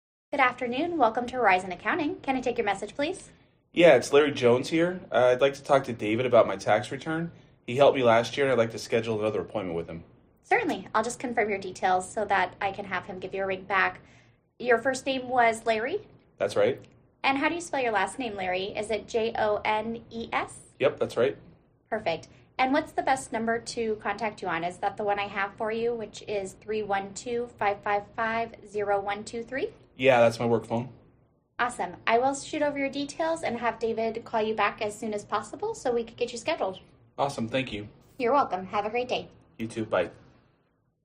Our friendly and professional receptionists will answer your business calls with your preferred greeting, 24/7 if you wish.
financial-services-answering-service-sample-call.mp3